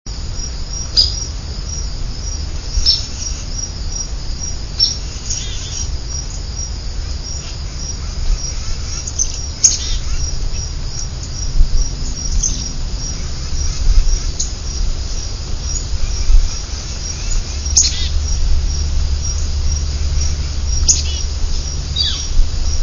Downy Woodpecker, Chickadee gurgles and contact calls, Titmice and Flicker "peogh".